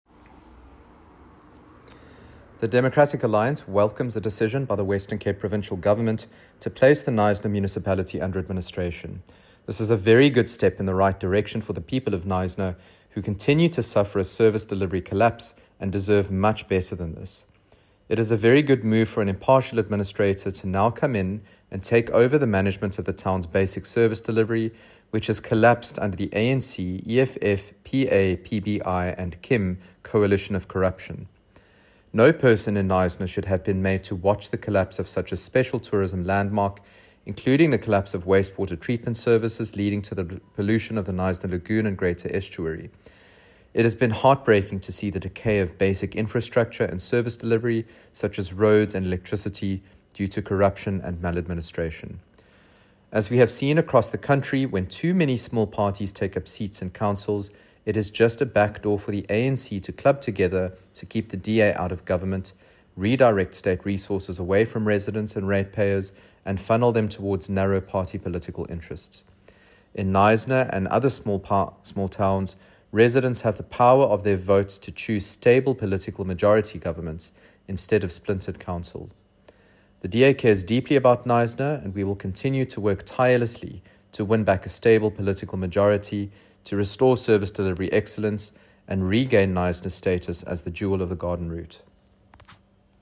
soundbite